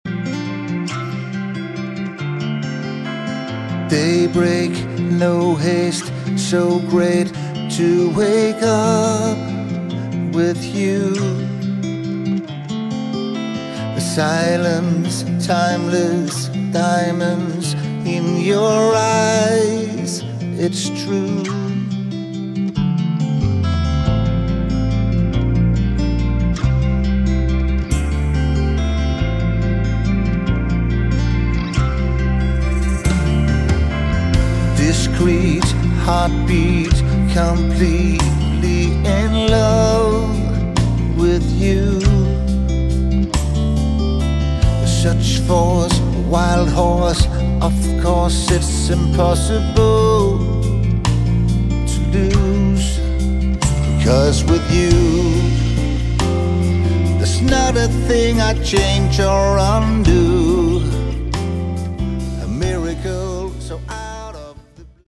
Category: Westcoast AOR
vocals, guitars, drums
backing vocals, guitars, bass, keyboards, percussion, drums
cardboard box